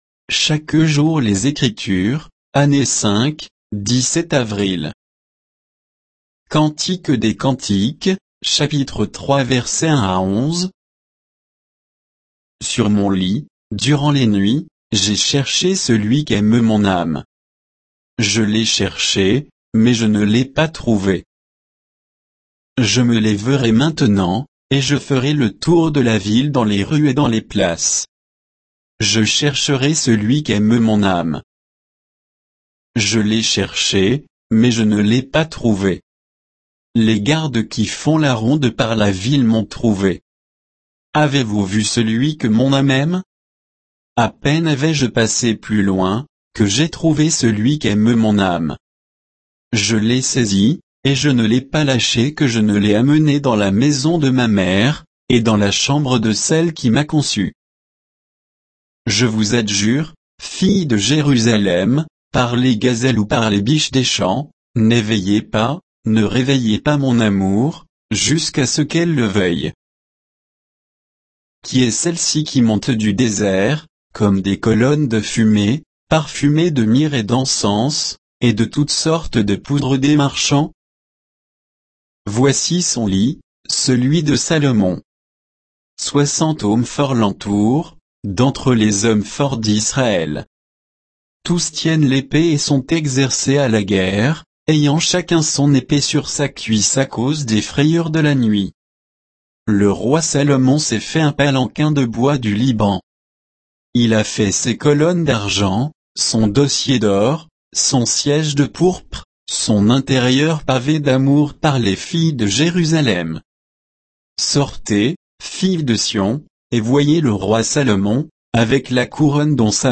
Méditation quoditienne de Chaque jour les Écritures sur Cantique des cantiques 3